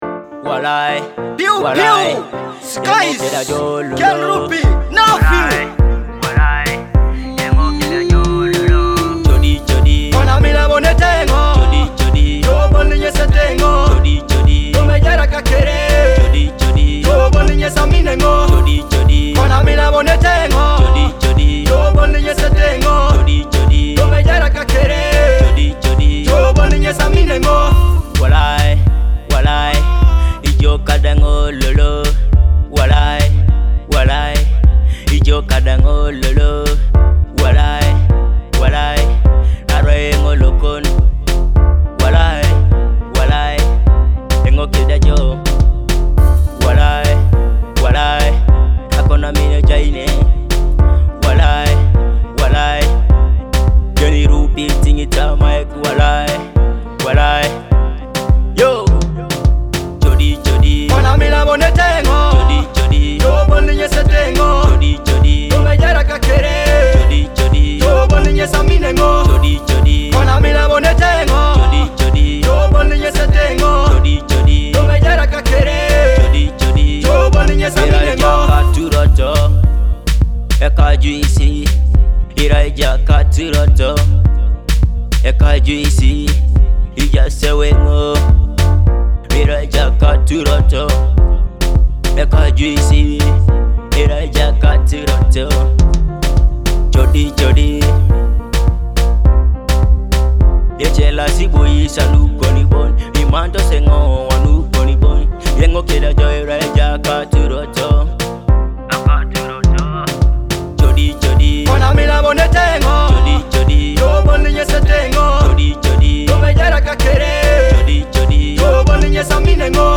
romantic love song